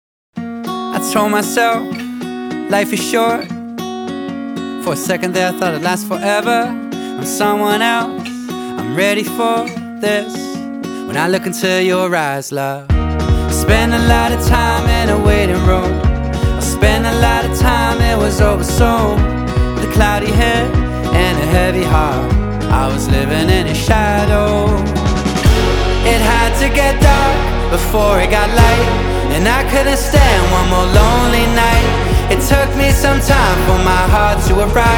Pop Alternative
Жанр: Поп музыка / Альтернатива